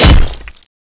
thanatos3_stone2.wav